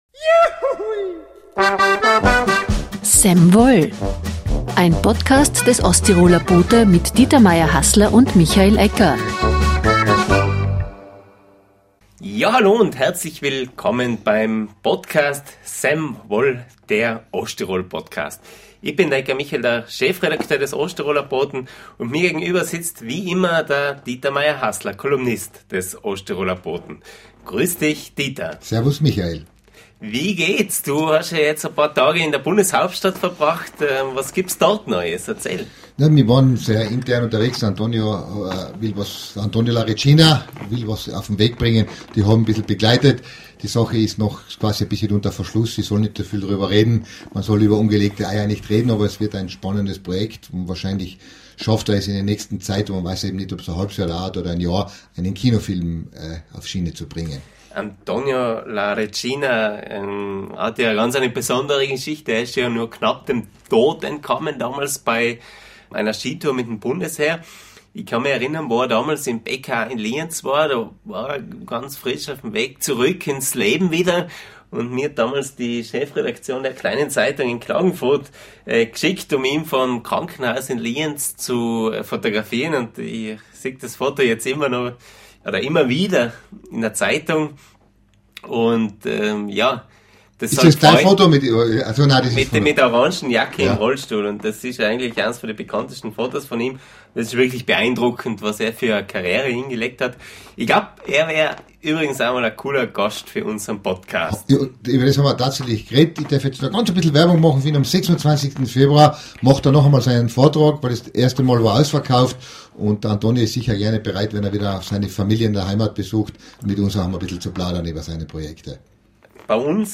im Talk.